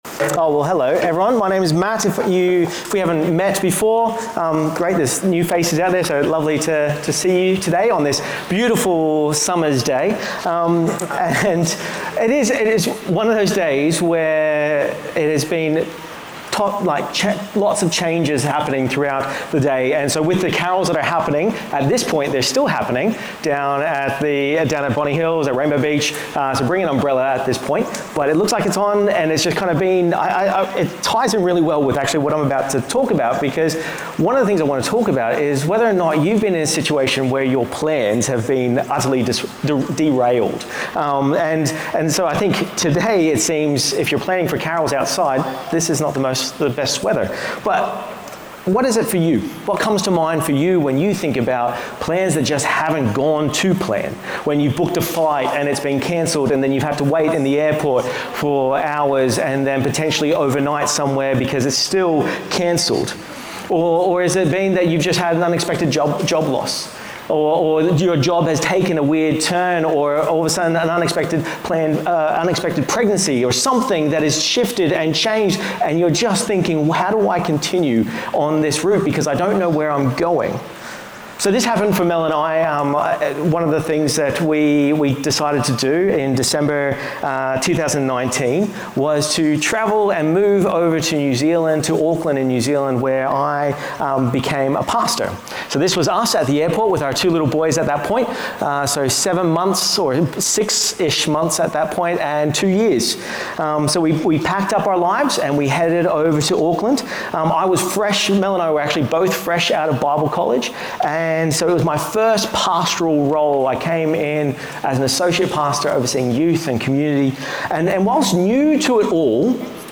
Weekly Bible Talks from Salt Community Church, Lake Cathie, Australia
Salt Sermons